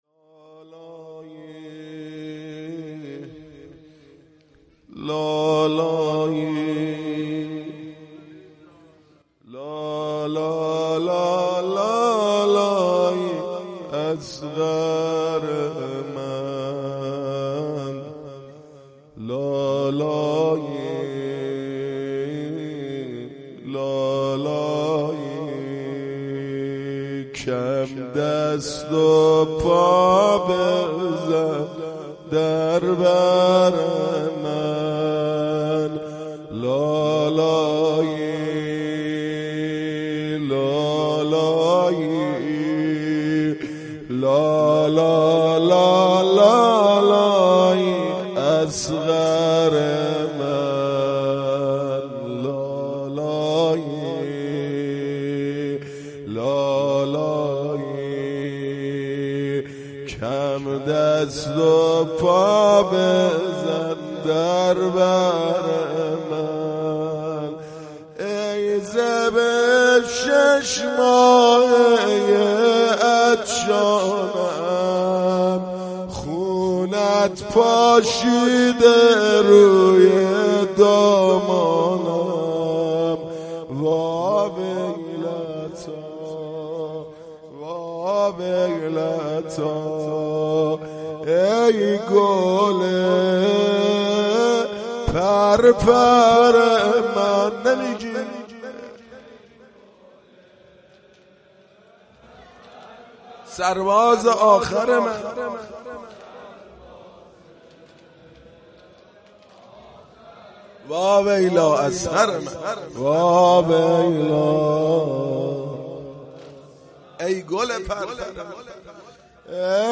مداحی شب هفتم محرم 98 ( نوحه )